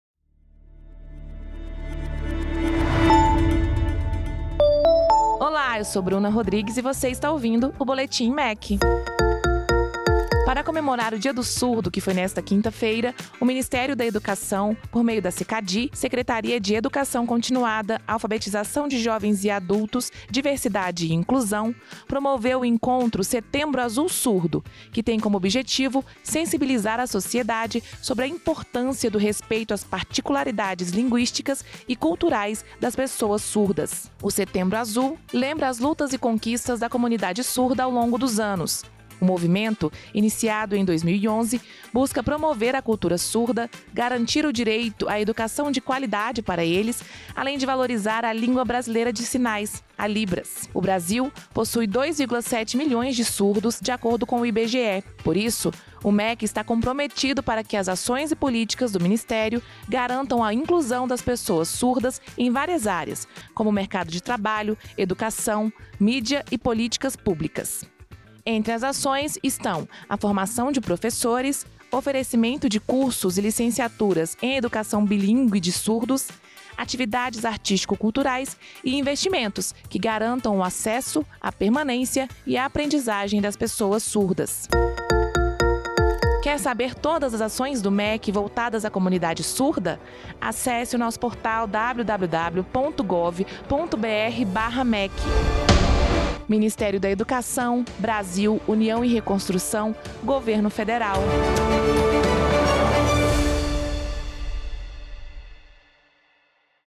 Boletins informativos diários com as principais notícias do Ministério da Educação.